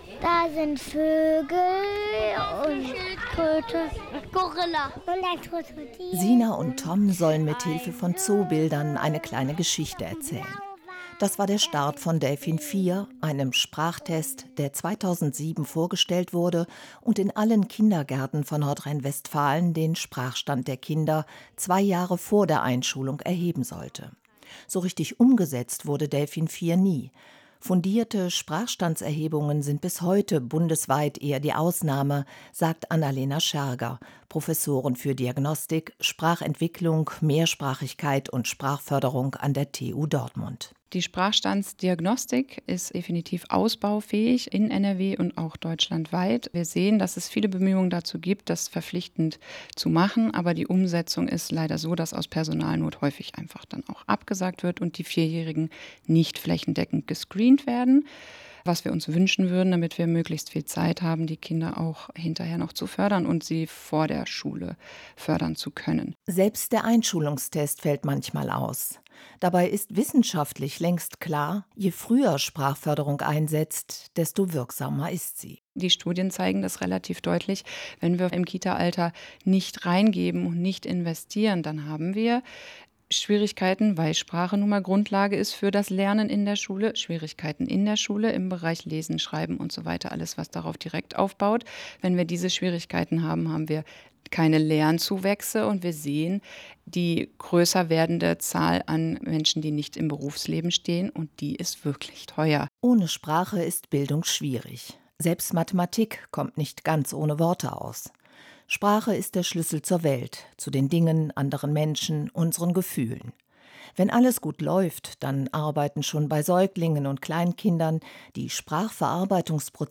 WDR-Interview
besuchte dafür eine Sprachkita in NRW
Radiobeitrag